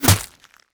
bullet_impact_ice_03.wav